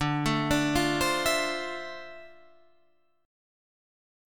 D Minor 9th